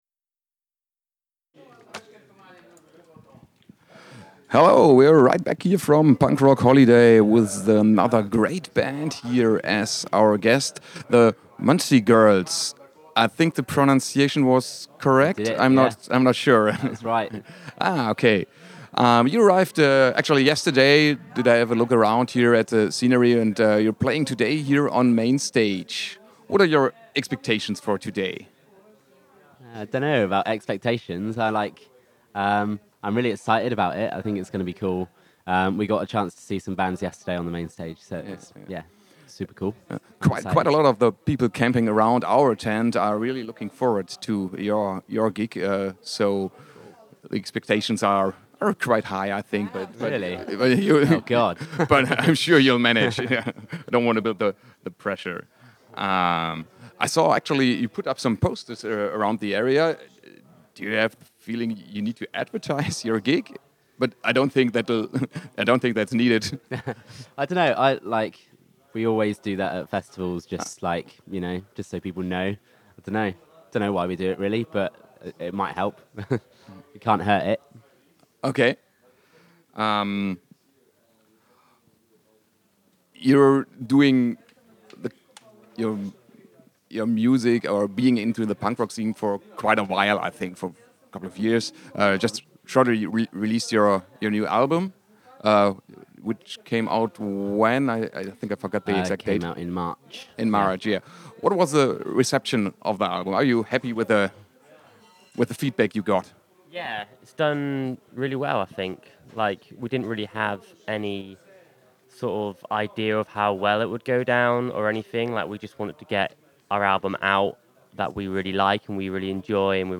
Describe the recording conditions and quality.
Interview with Muncie Girls at the Punk Rock Holiday 1.6. Interview mit den Muncie Girls beim Punk Rock Holiday 1.6. interview-with-muncie-girls-at-the-punk-rock-holiday-1-6-mmp.mp3